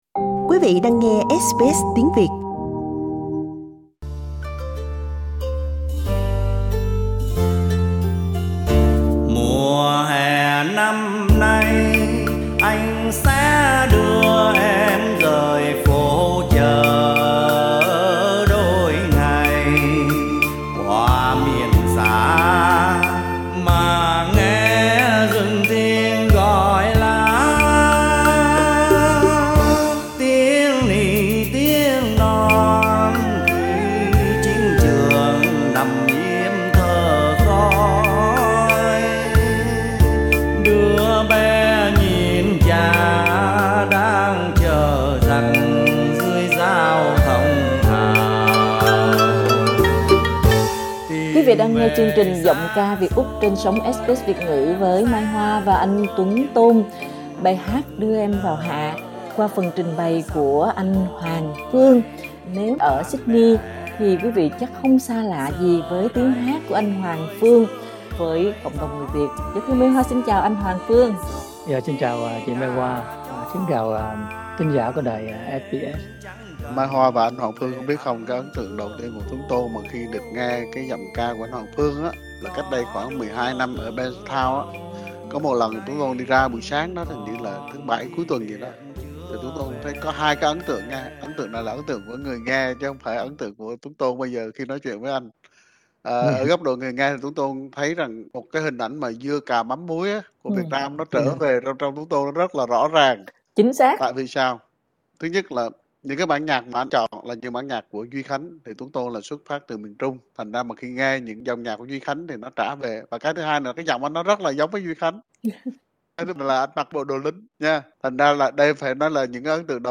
Anh hát trong phố chợ hay trên sân khấu đều trãi lòng ra với tình tự quê hương qua dòng nhạc Bolera và nhạc lính.